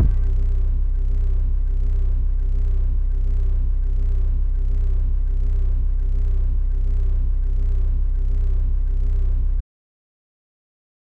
808 (Oh My).wav